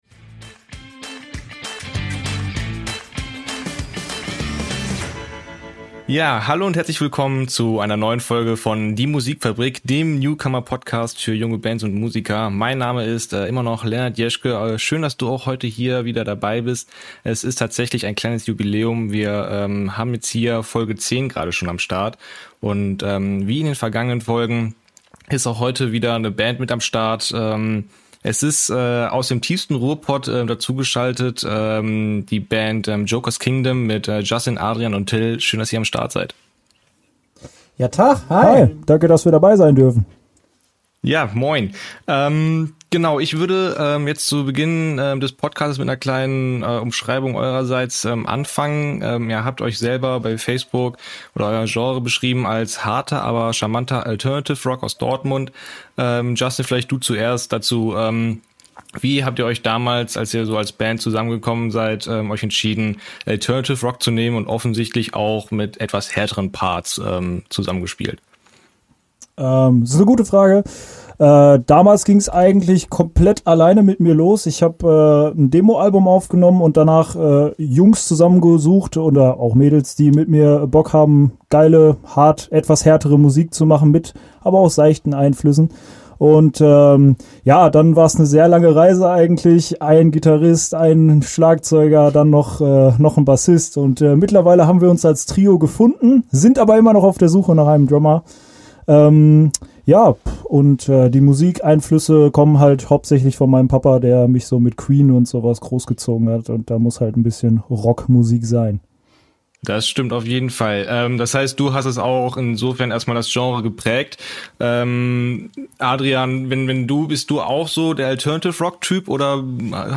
Zu Gast waren die Dortmunder Jungs von der Alternative-Rock Band Joker´s Kingdom. Eine wirklich lustige Runde in der es um die Bandgeschichte, Erfahrungen mit den ersten eigenen Konzerten und Zeitungsartikeln ging und einer sehr spontanen Stellenanzeige für den zukünftigen Drummer.